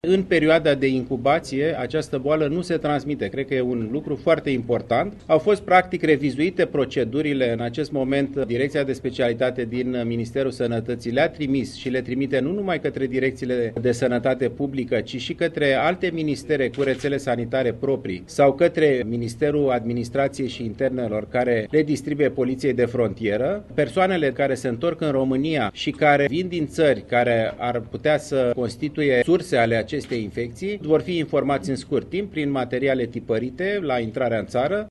Consilierul ministrului sănătății, Alexandru Rafila.